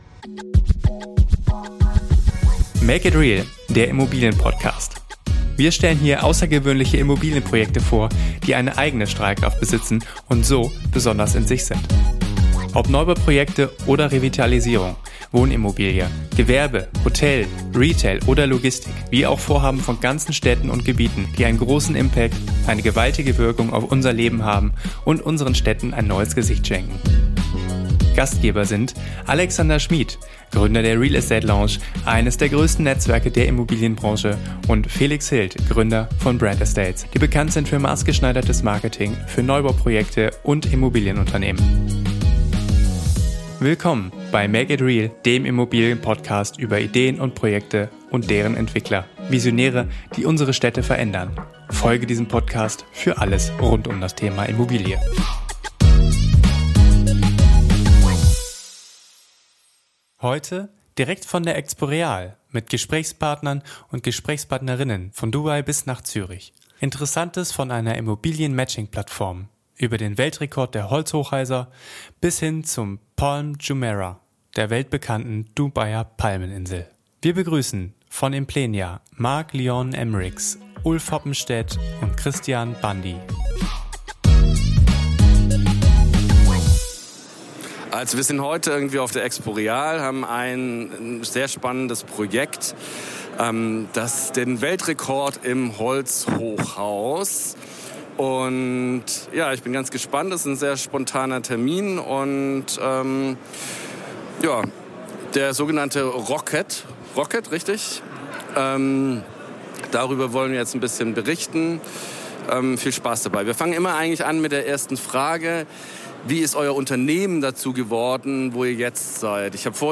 Beschreibung vor 3 Jahren In dieser Podcast-Folge berichten wir direkt aus München von der EXPO REAL mit Gesprächspartner:innen von Dubai bis nach Zürich. Interessantes von einer Immobilien-Matching-Plattform für Investoren und Objekte, über den Weltrekord der Holzhochhäuser, bis hin zur Palm Jumeirah, der weltbekannten Dubaier Palmeninsel.